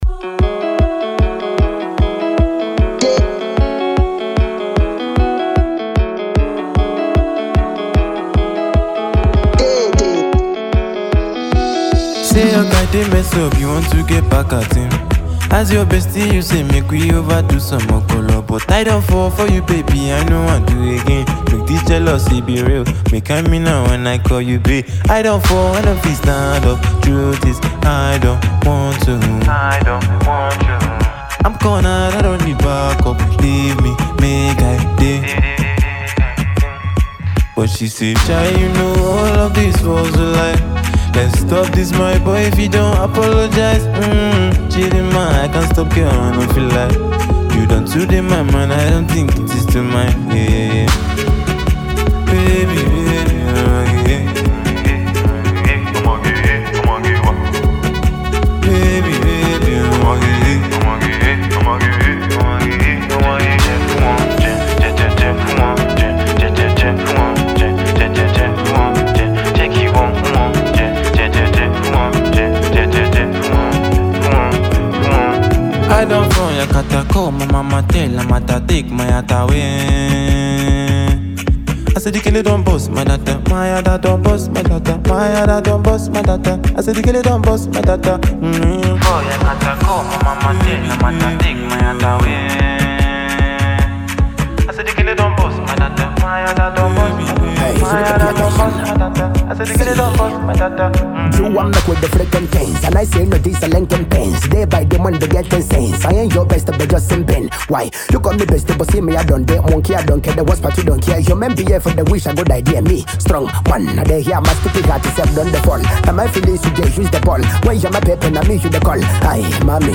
afro song